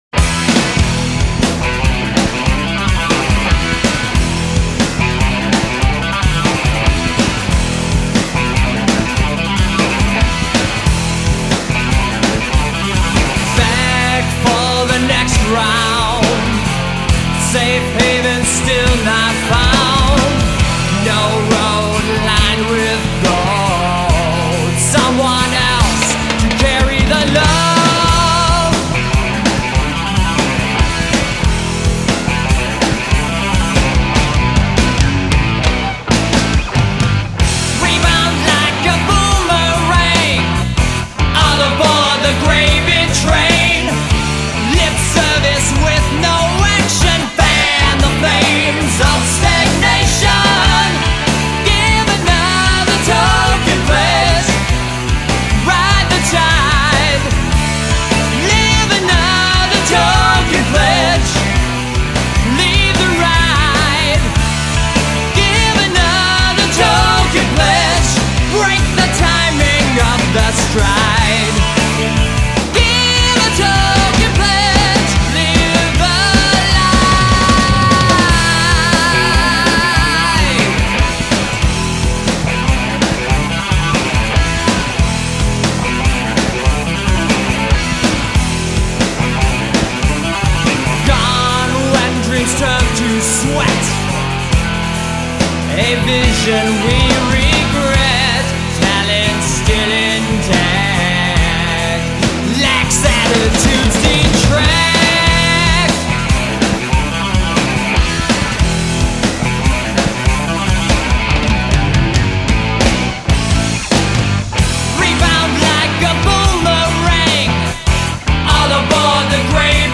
Drums, Percussion
Guitars, Keyboards
Vocals
Bass, Vocals